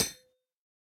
sounds / block / copper_grate / step2.ogg